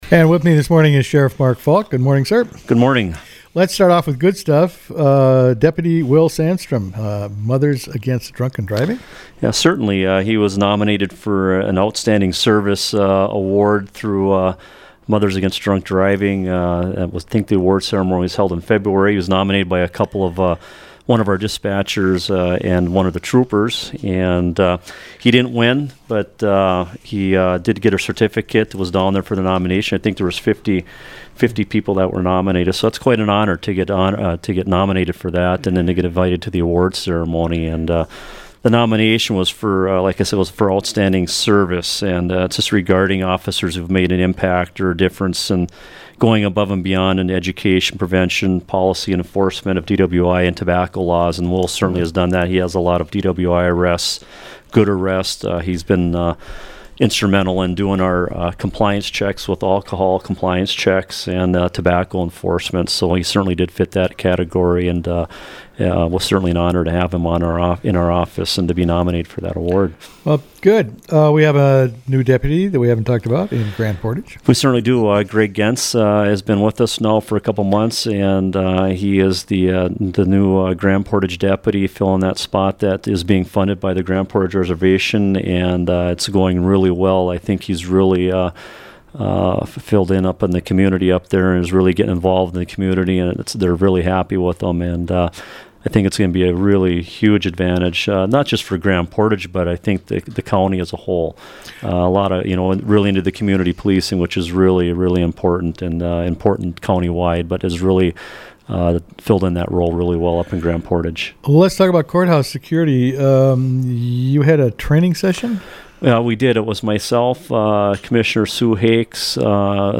Sheriff discusses increased medical 911 calls and more